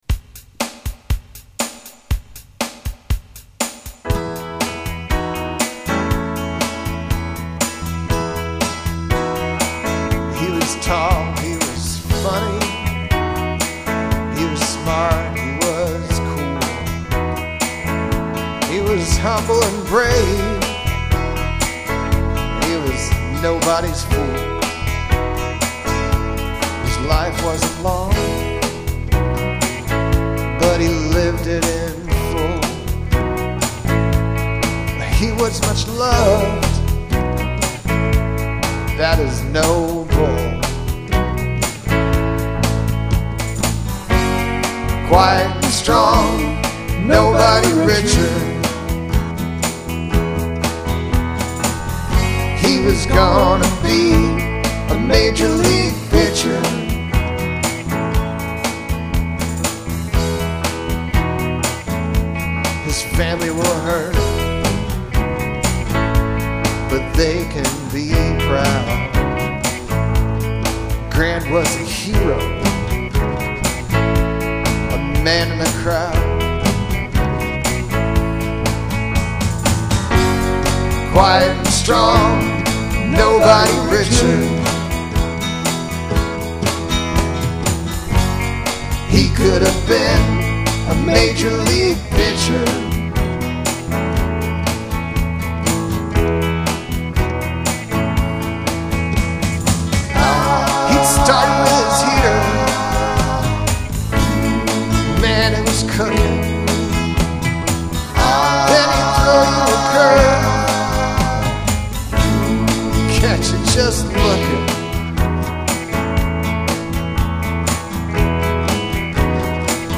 120 b/m 8-26-21